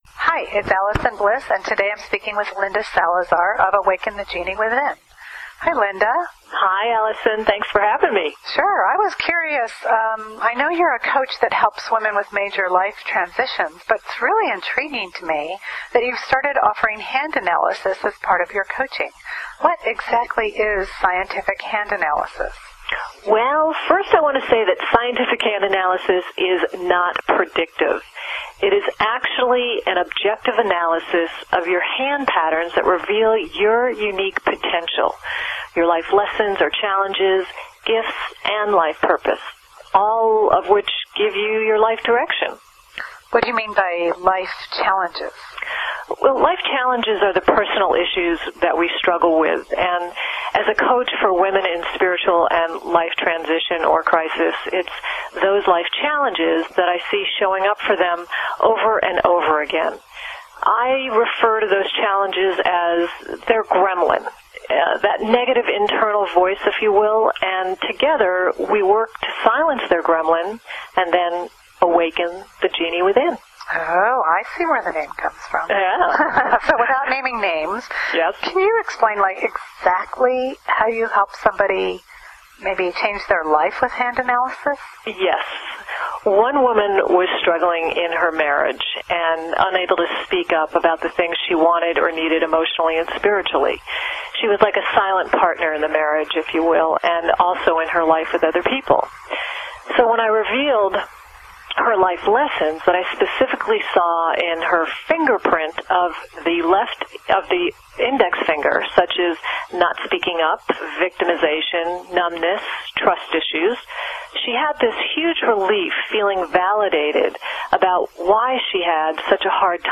We'll help you craft a good story about your company for this telephone interview- which can be done anywhere in the world.